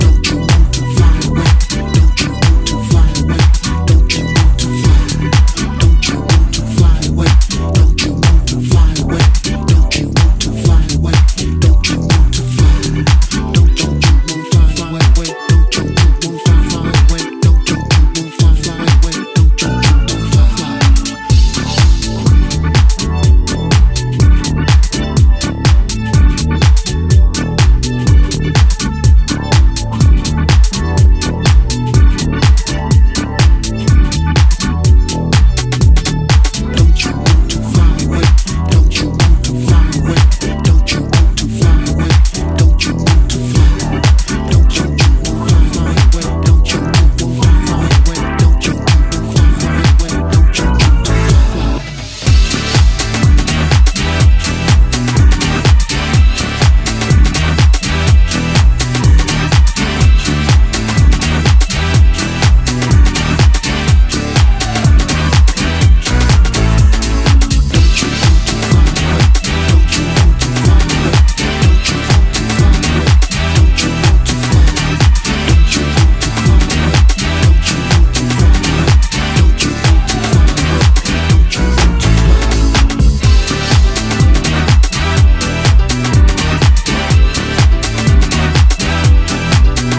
disco, funk and groove-laden